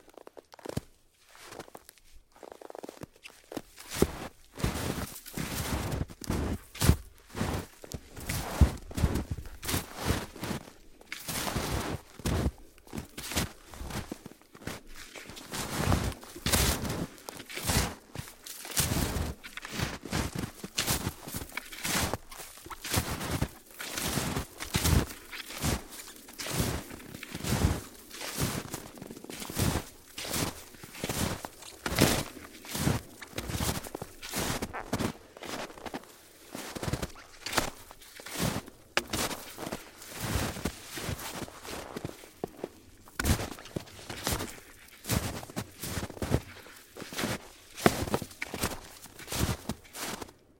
冬天" 脚步声 雪鞋 老木头2 深雪停止开始 软绵绵的绒毛很好
描述：脚步雪鞋老木2深雪停止开始柔软易碎蓬松nice.flac
标签： 脚步 雪鞋 wood2
声道立体声